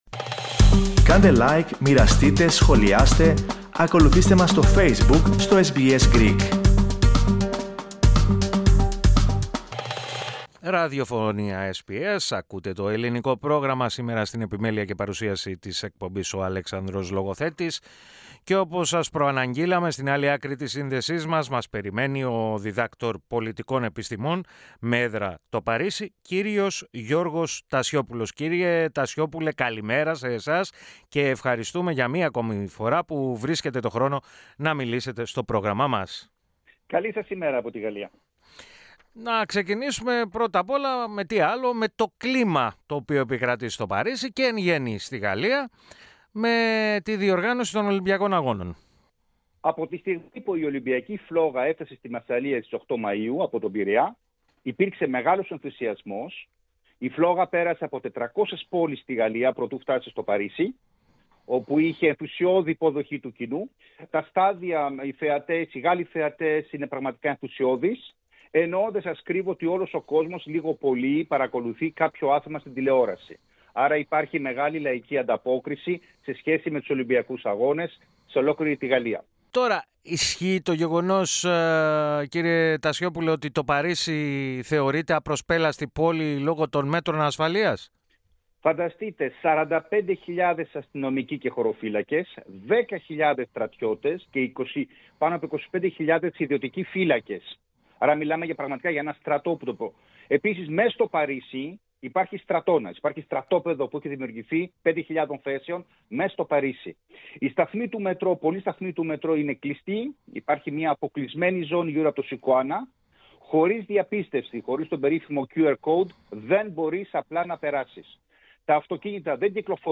Το κλίμα το οποίο επικρατεί στο Παρίσι, και εν γένει στη Γαλλία, σε σχέση με τους Ολυμπιακούς Αγώνες, μετέφερε στους ακροατές του Ελληνικού Προγράμματος της ραδιοφωνίας SBS, ο Δρ.